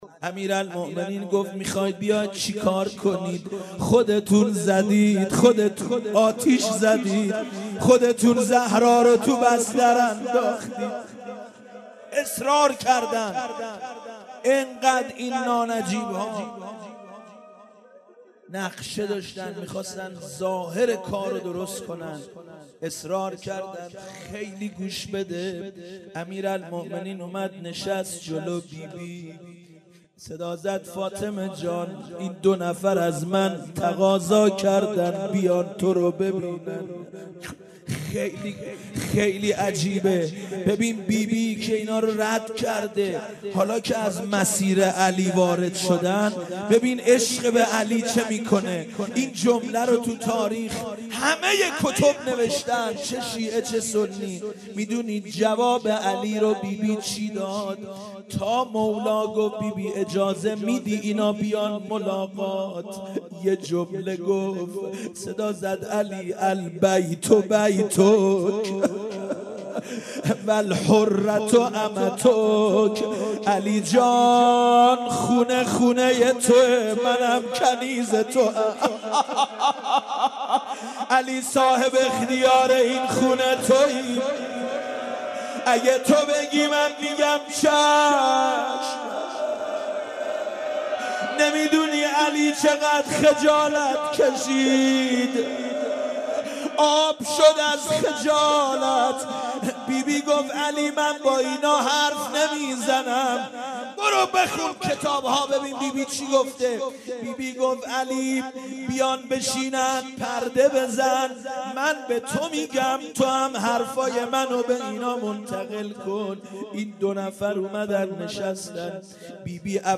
دانلود مداحی چادر خاکی مادر - دانلود ریمیکس و آهنگ جدید